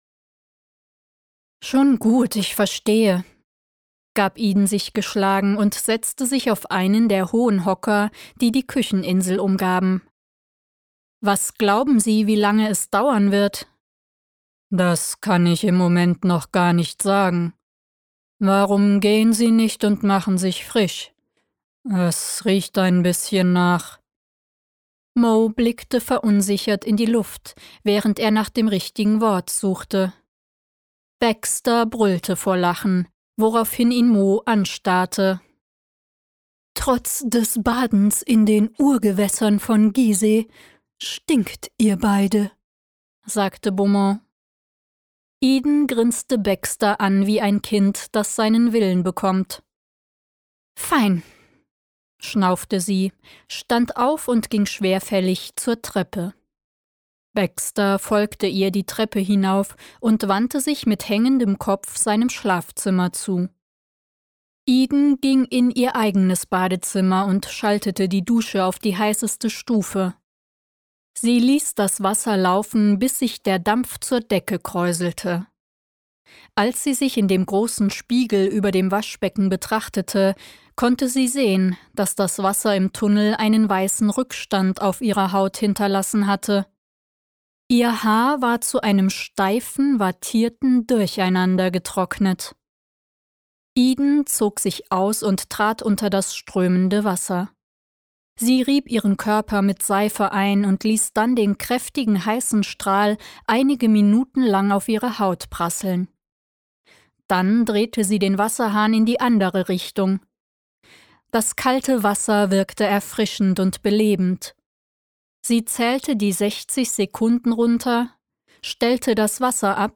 • Top-Studioqualität und professioneller Schnitt
Hörbuch